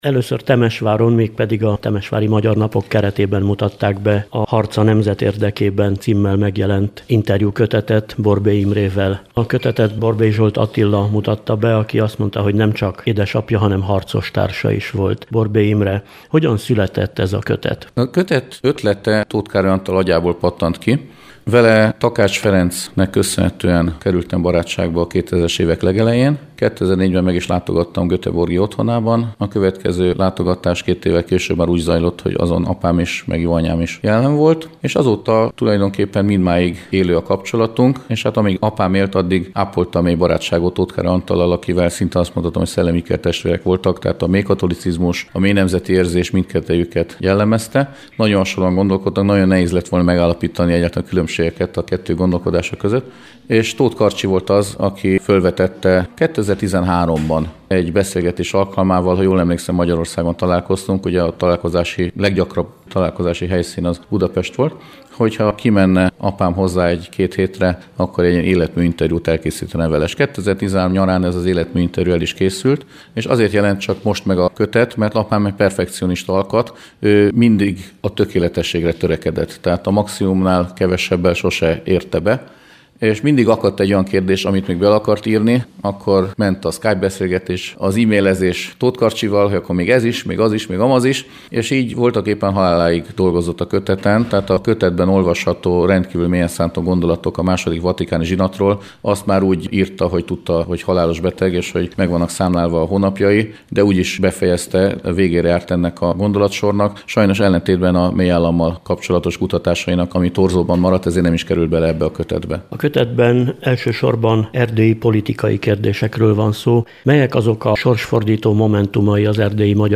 A könyvet először szeptemberben, a Temesvári Magyar Napokon mutatták be.